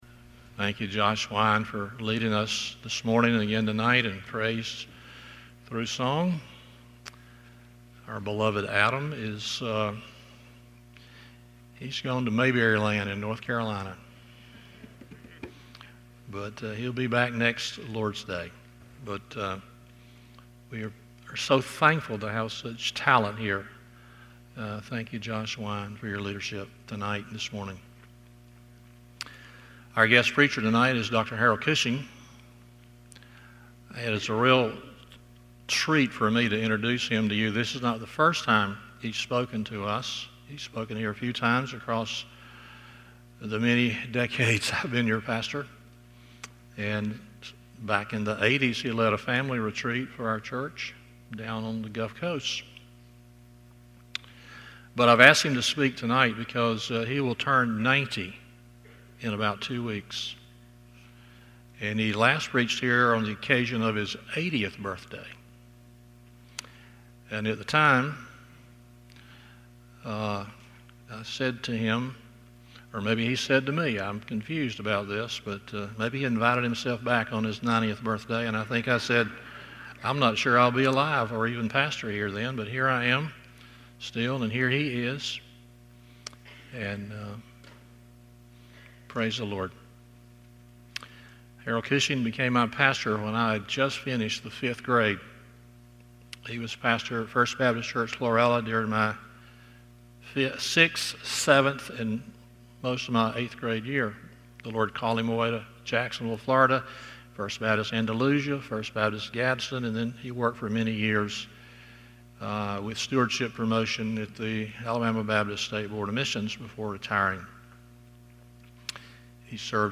Stand Alone Sermons
Service Type: Sunday Evening Topics: Evangelism , Gospel , Salvation